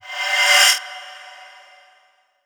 VEC3 FX Reverse 44.wav